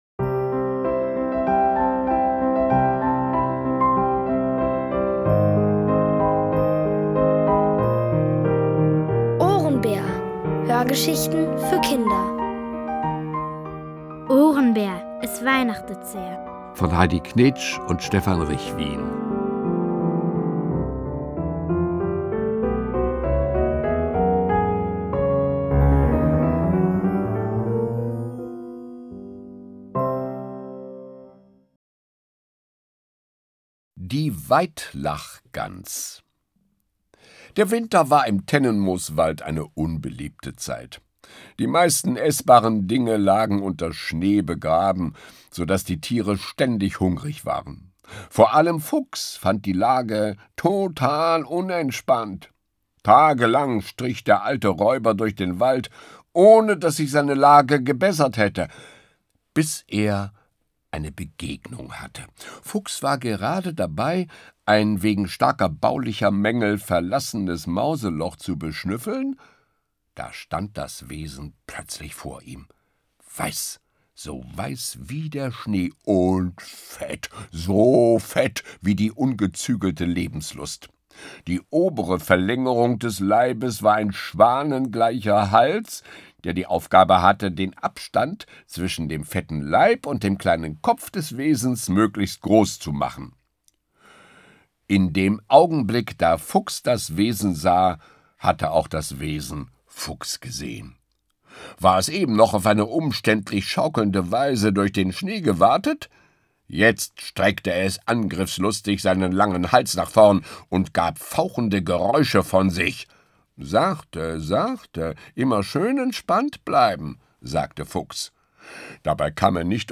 Von Autoren extra für die Reihe geschrieben und von bekannten Schauspielern gelesen.
Es liest: Jürgen Thormann.